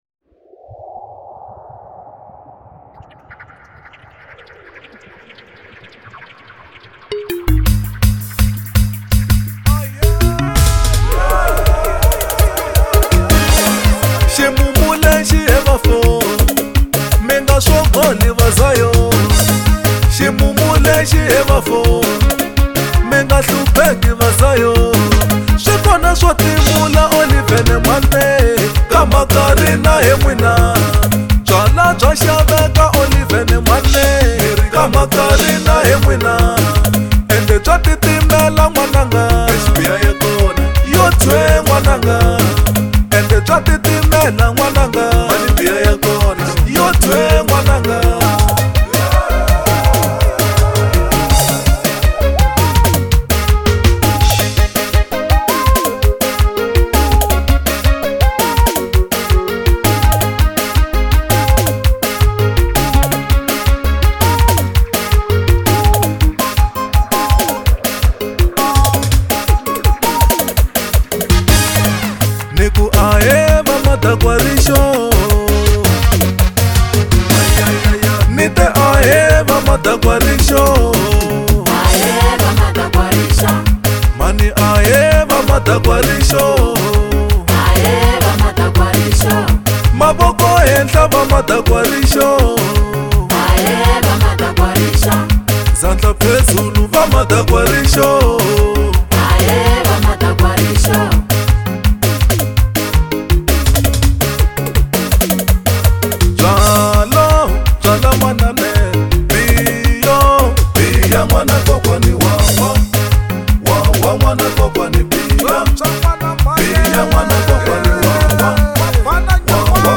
04:17 Genre : Xitsonga Size